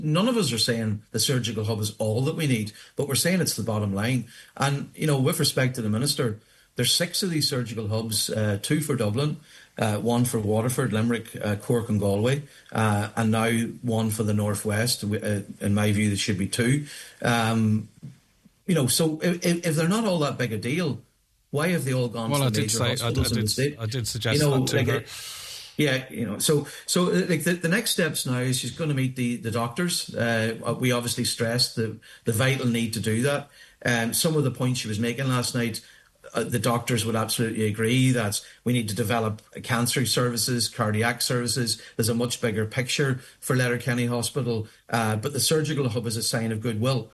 Responding to the Minister Deputy Padraig MacLochlainn says receiving the hub would be a gesture of what is to come: